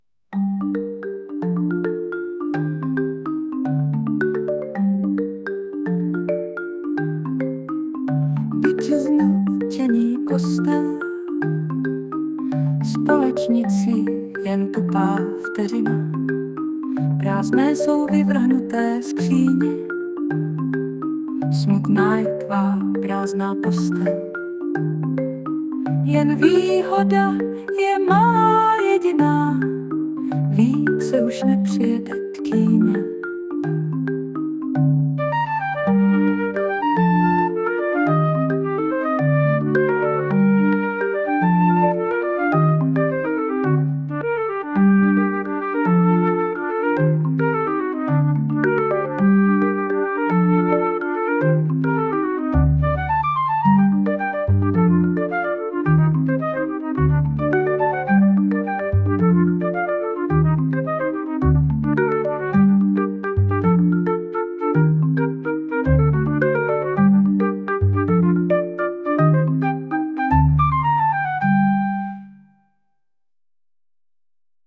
* hudba, zpěv: AI